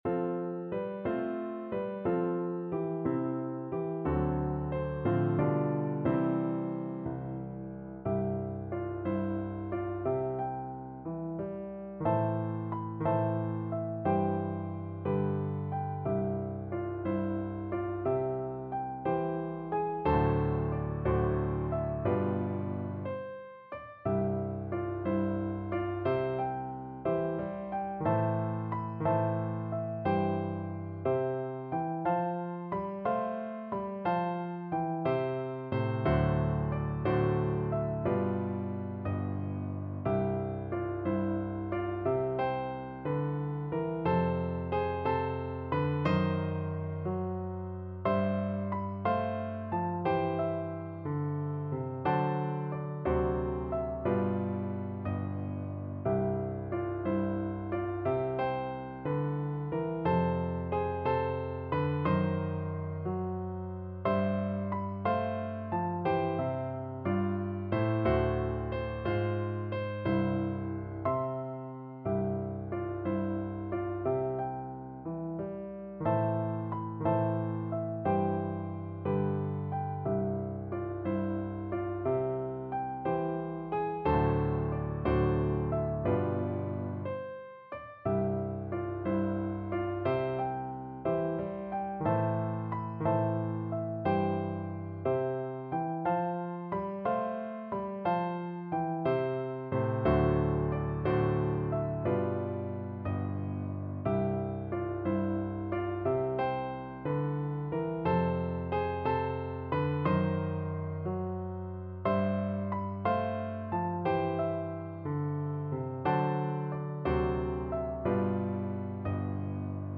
6/8 (View more 6/8 Music)
Gently and with expression .
Instrument:
Piano Duet  (View more Intermediate Piano Duet Music)
Traditional (View more Traditional Piano Duet Music)